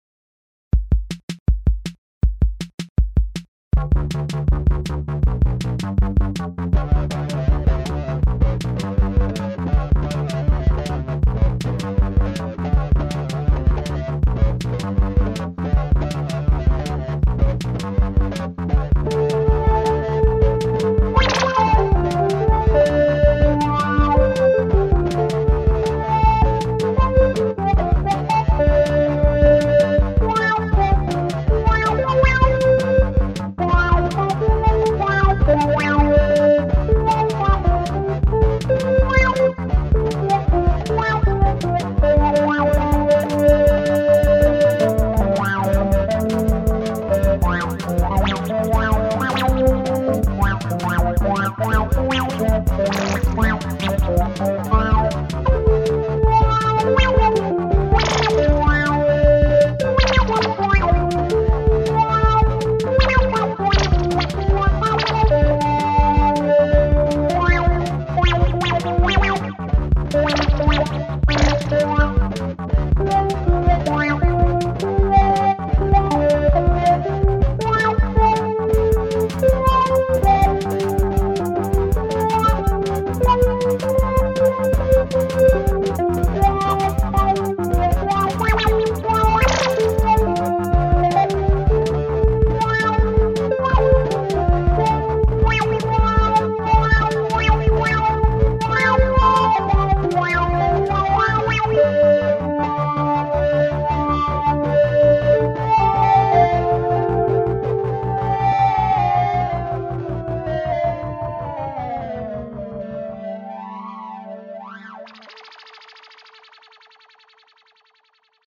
“sloppy” is the theme as I am tired of digital music being “perfect”